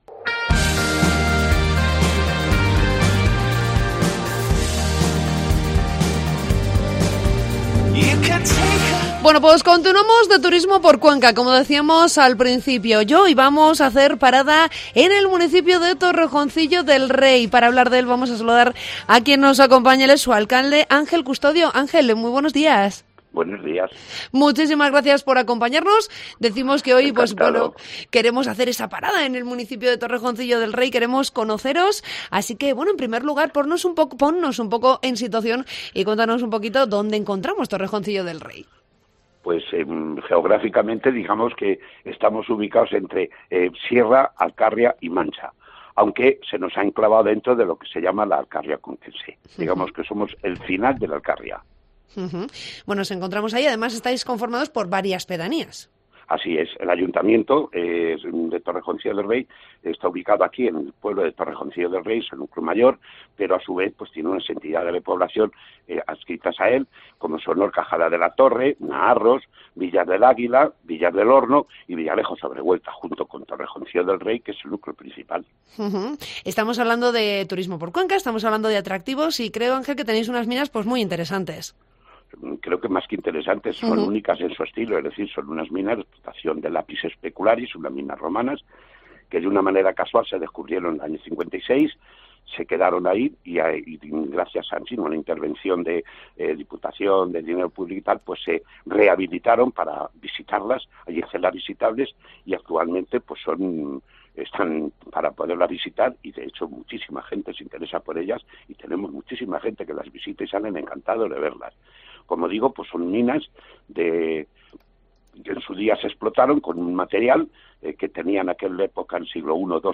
Entrevista con el alcalde de Torrejoncillo del Rey, Ángel Custodio